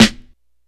BWB VAULT SNARE (Drill).wav